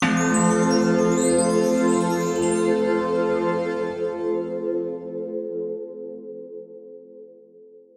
Levelup
chime chimebar chimes effect game level-up levelup magic sound effect free sound royalty free Gaming